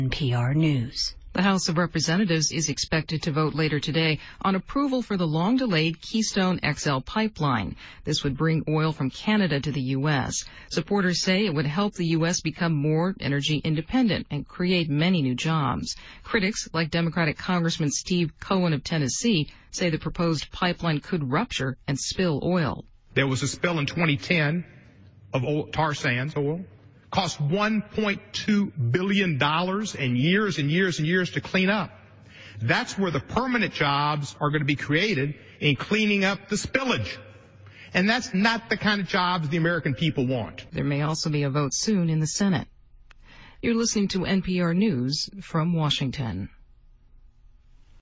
As the House debated the measure, I spoke on the floor to highlight the damage that the pipeline will do to our environment and,